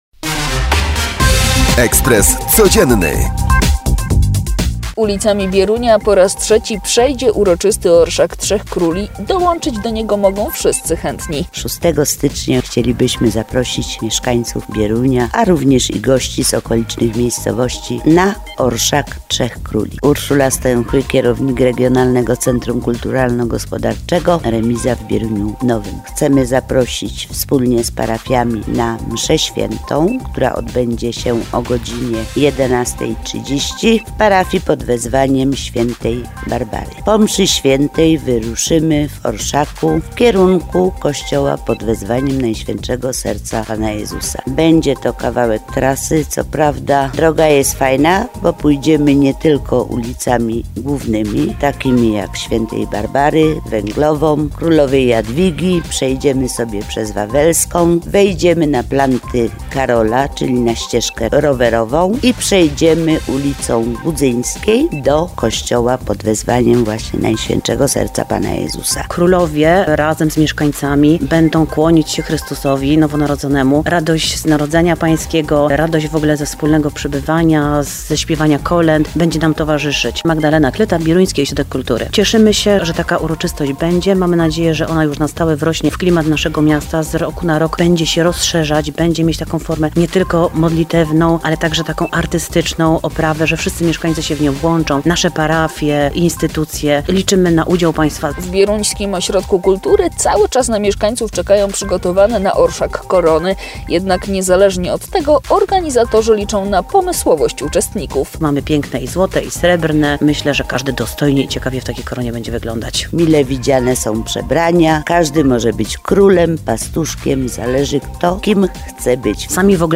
Jest nim Stanisław Piechula - burmistrz Mikołowa.